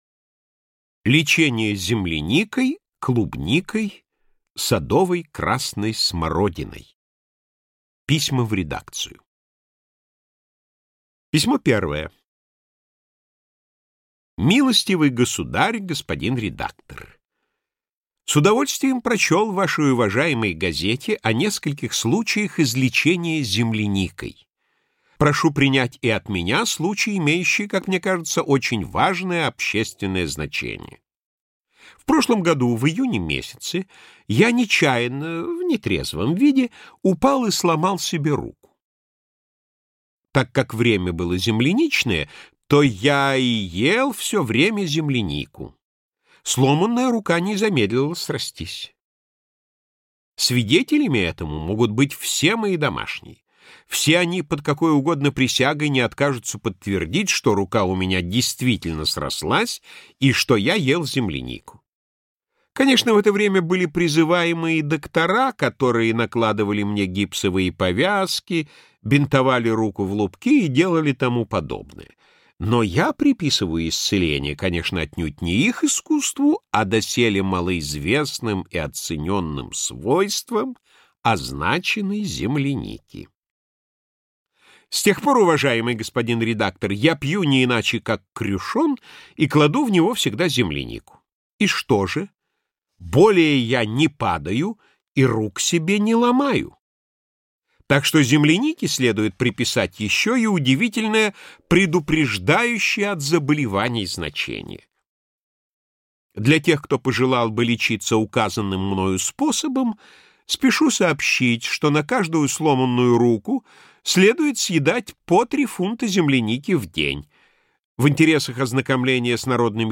Аудиокнига Анекдотическое время. Юмористические рассказы | Библиотека аудиокниг